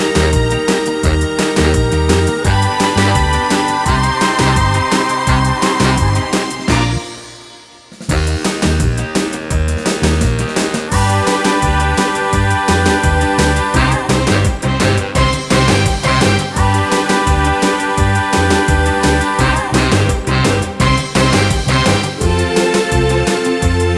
Backing tracks for female or girl singing parts.